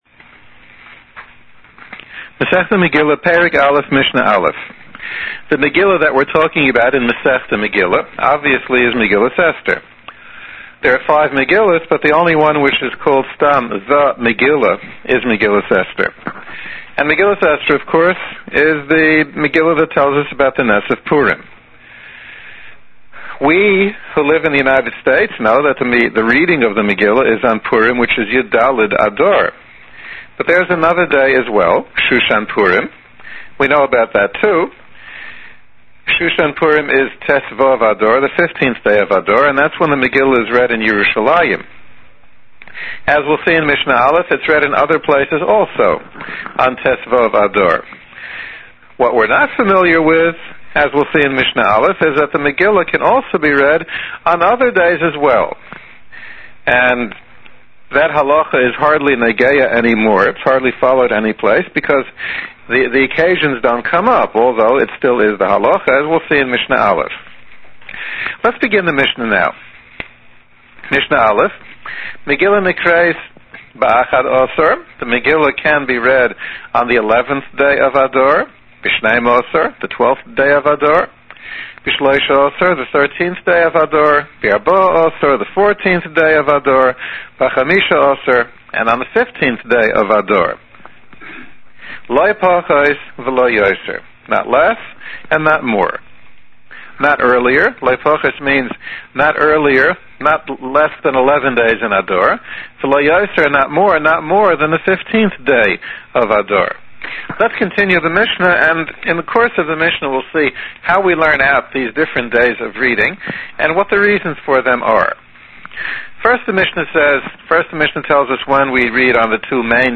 These shiurim can be listened to by someone who wants to follow the Mishna Yomis schedule, by a boy who wants to make a siyum for his Bar Mitzvah, or anyone who wants to learn the Mishnayos with a clear explanation.